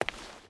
Stone Walk 5.ogg